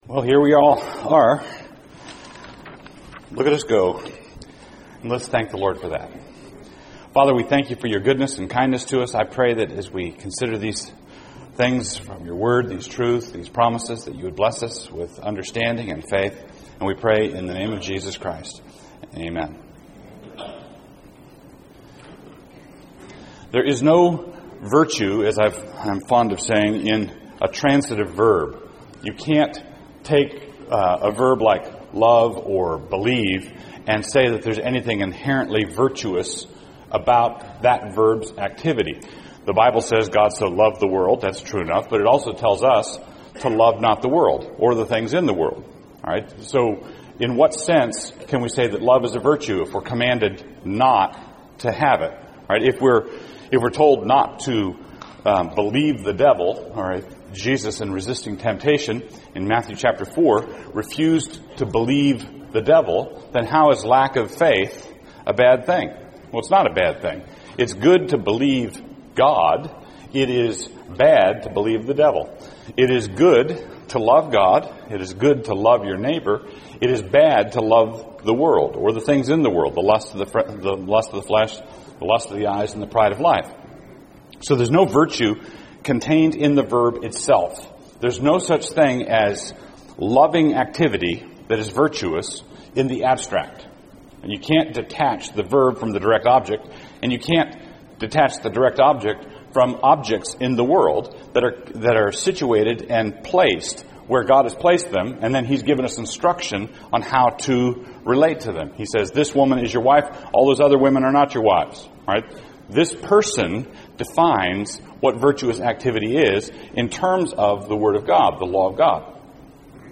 2006 Plenary Talk | 0:55:27 | Culture & Faith